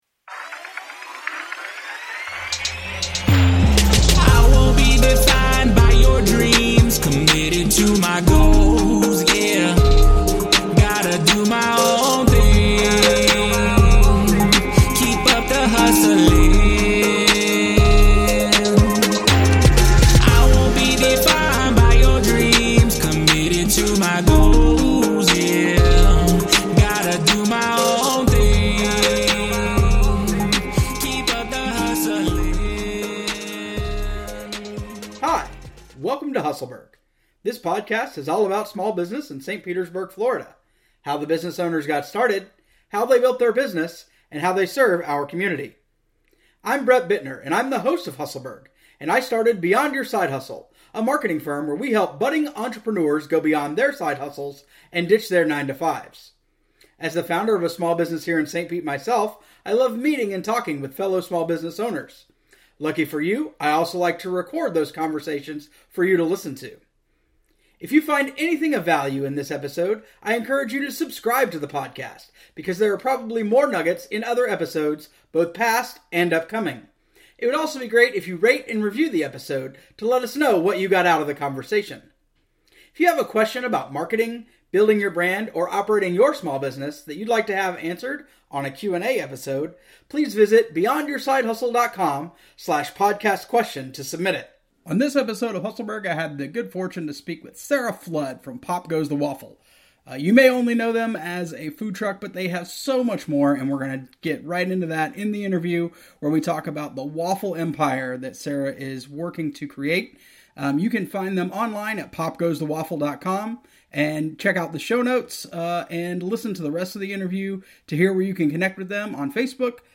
Here are the three events we talked about in the interview: